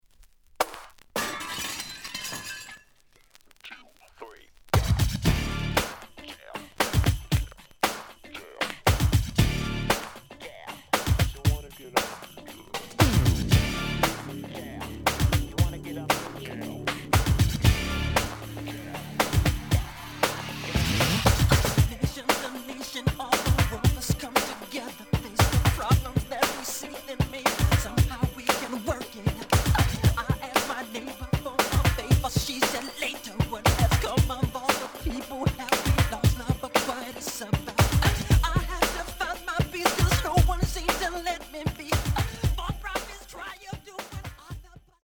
The audio sample is recorded from the actual item.
●Genre: Funk, 80's / 90's Funk
Slight damage on both side labels. A side plays good.)